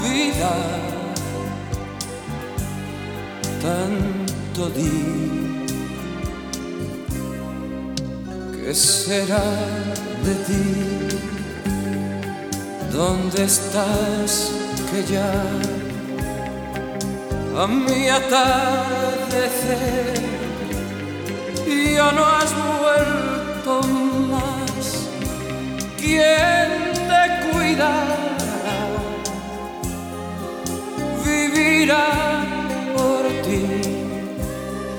Baladas y Boleros, Latin, Pop Latino